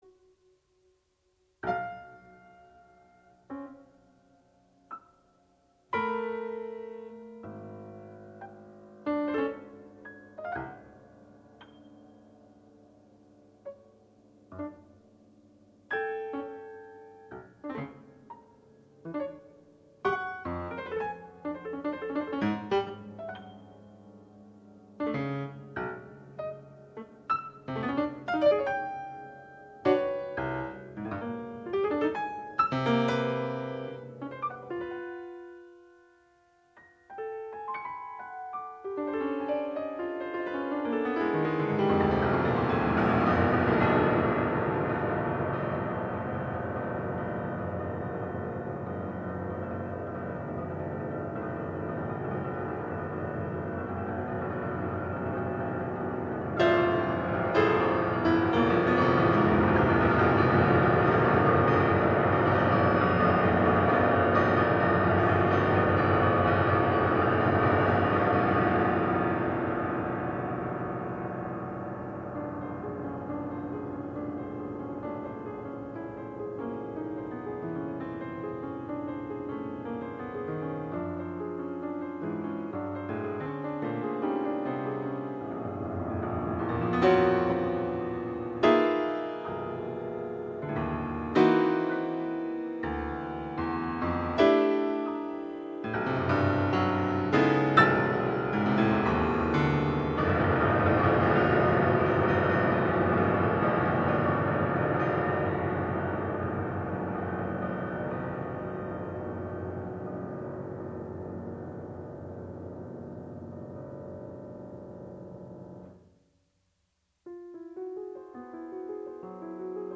Música académica